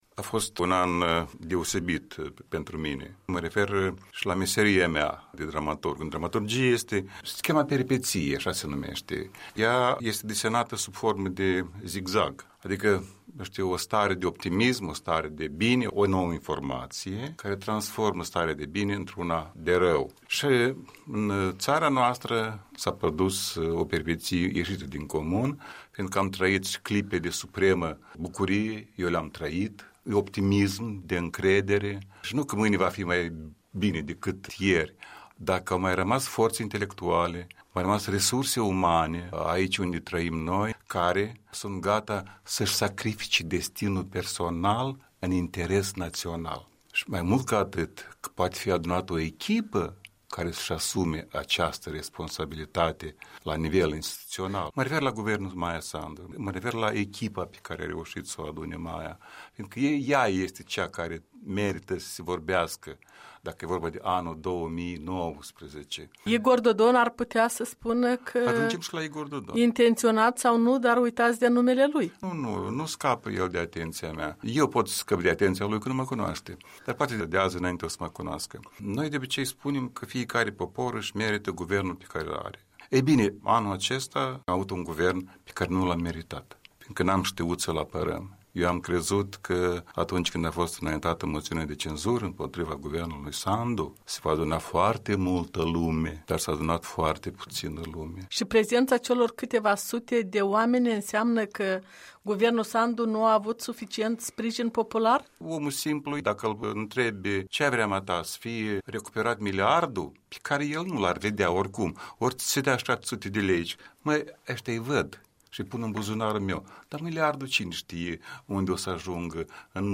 Interviu cu dramaturgul Mihai Poiată